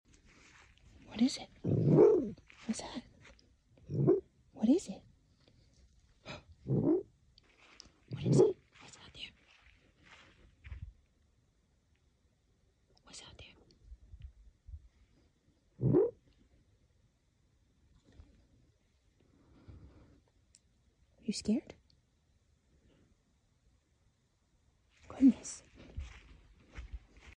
Red foxes have over 13 unique sounds they use to communicate making them one of the most vocal canids. This is a vocalization I don’t hear from them often and one of my favorites since it sounds so alien.